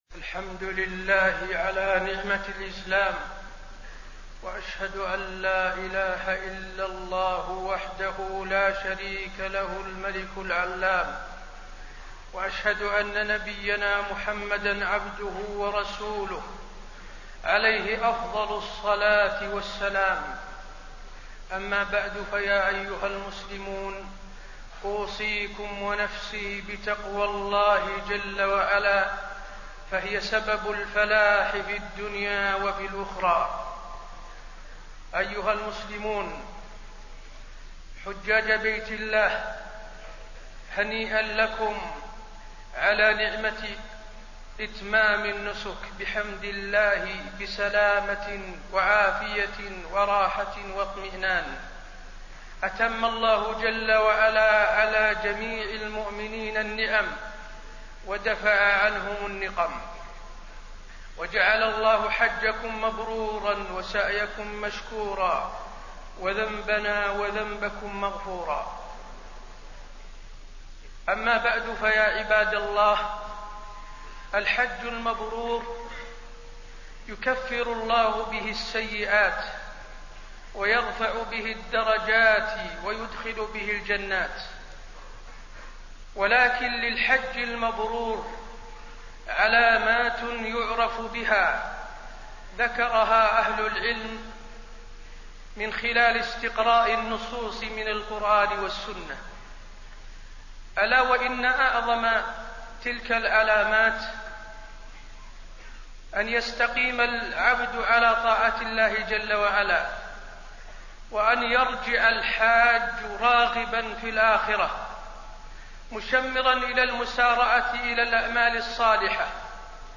تاريخ النشر ١٥ ذو الحجة ١٤٣٢ هـ المكان: المسجد النبوي الشيخ: فضيلة الشيخ د. حسين بن عبدالعزيز آل الشيخ فضيلة الشيخ د. حسين بن عبدالعزيز آل الشيخ سبل المحافظة على الطاعات